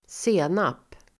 Ladda ner uttalet
Uttal: [²s'e:nap]